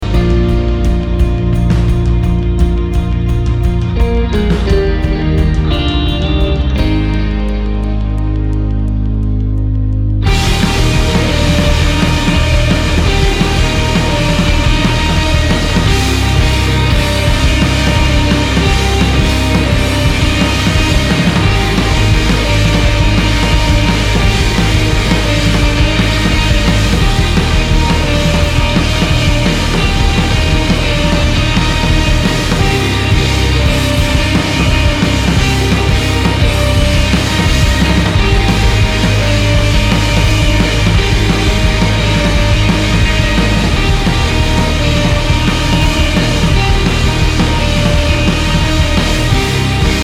Post Rock, Experimental Rock >